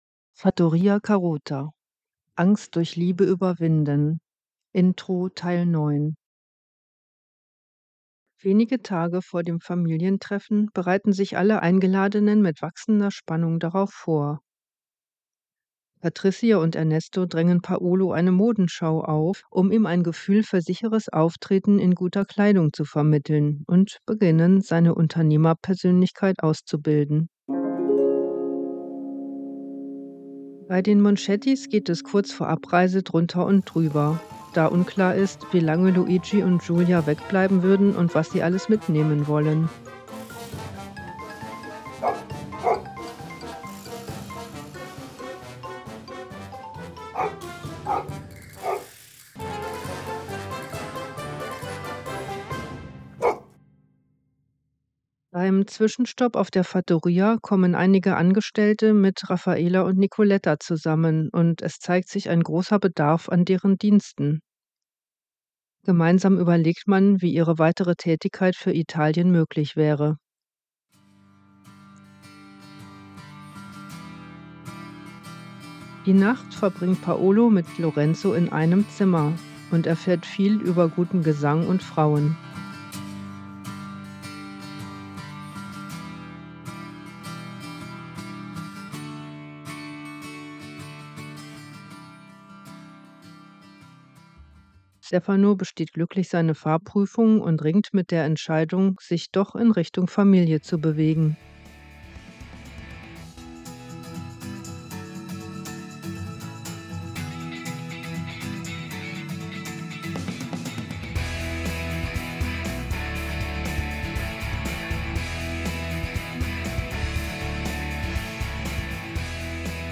Konflikte lösen mit Humor mit Wandel-Hörspielen (auch zum Mitmachen), ausgefallenen Gedichten, experimentellen Rollenspielen, Konfliktlösungs-Sketchen